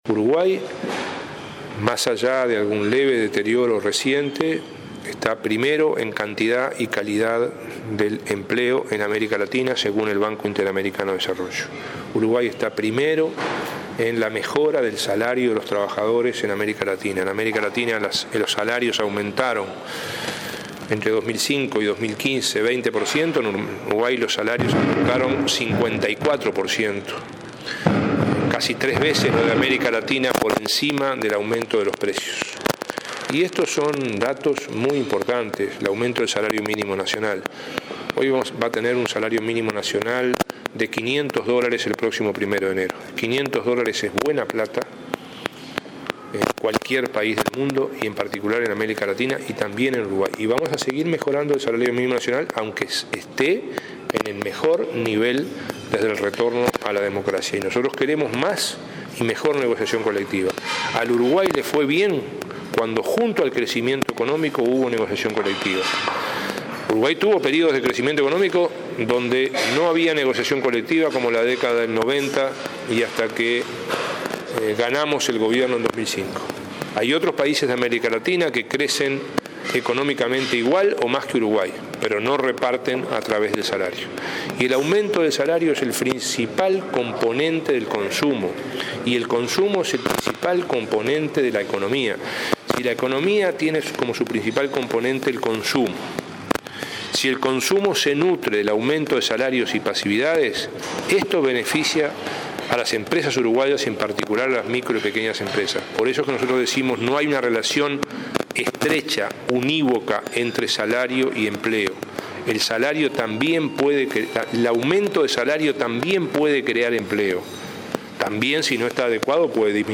Uruguay está primero en la mejora de salarios de los trabajadores en América Latina según el BID, subrayó el ministro de Trabajo, Ernesto Murro, en un desayuno de trabajo de Somos Uruguay.